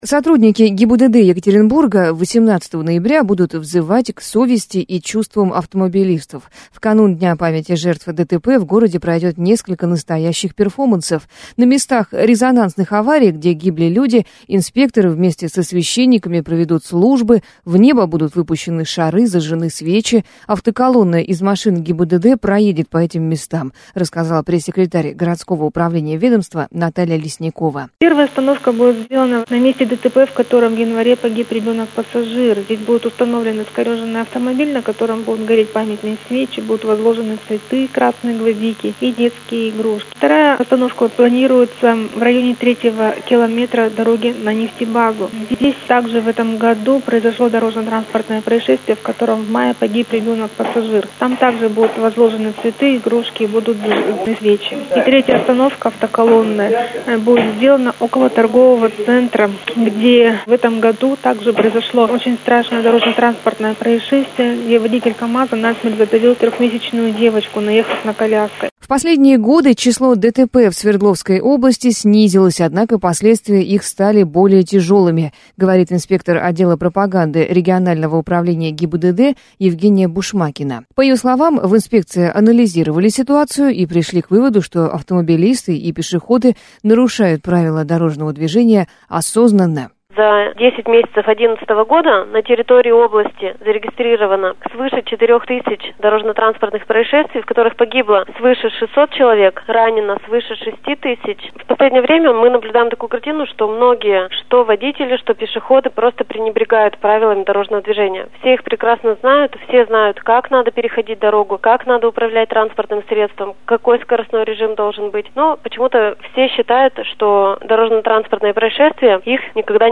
ГИБДД Екатеринбурга объединилась со священниками - репортаж